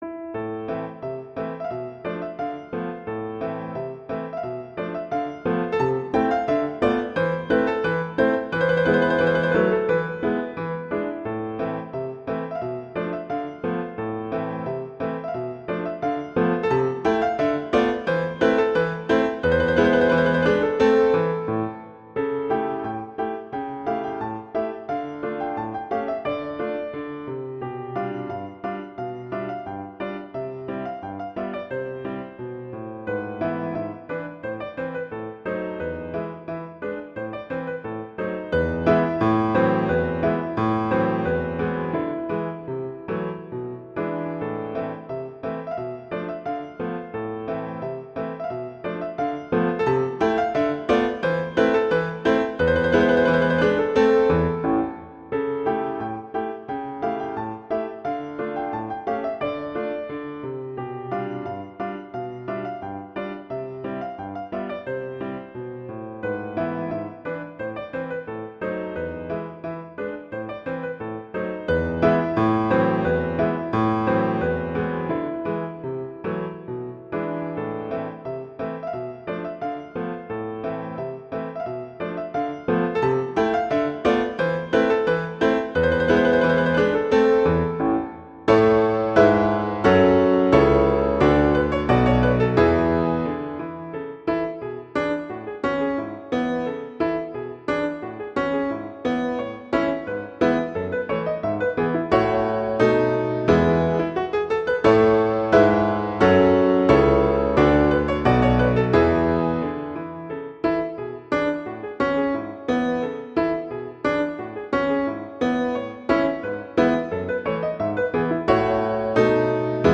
Instrumentation: piano solo
classical, french, wedding, festival, love
A minor
♩=176 BPM